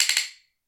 拍子木っぽい音３
sounds_Hyoshigi3.mp3